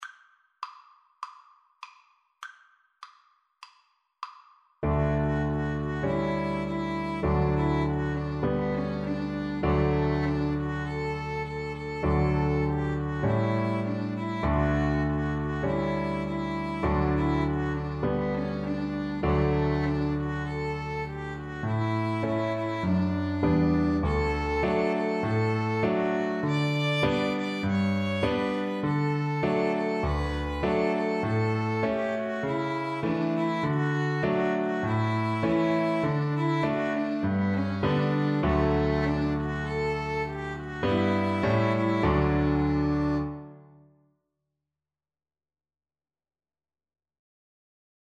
Free Sheet music for Piano Trio
ViolinCelloPiano
4/4 (View more 4/4 Music)
D major (Sounding Pitch) (View more D major Music for Piano Trio )
Moderato
Traditional (View more Traditional Piano Trio Music)